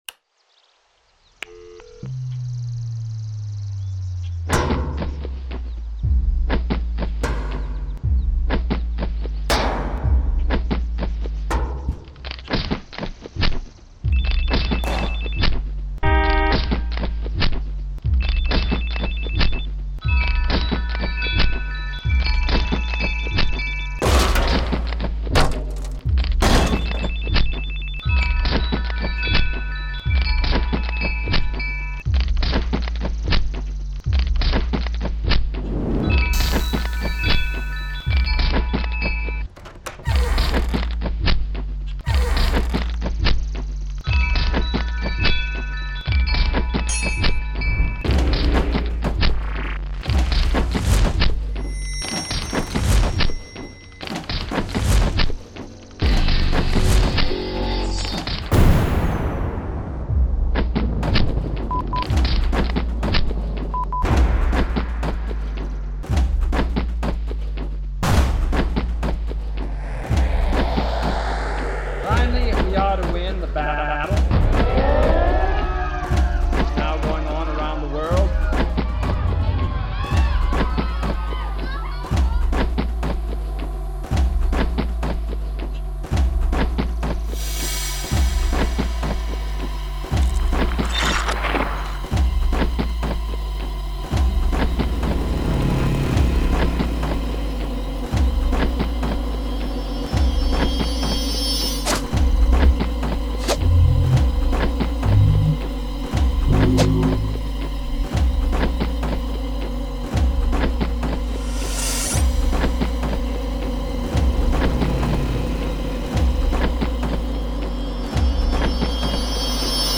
It is music only. I used a lot of my sound effects and created this piece.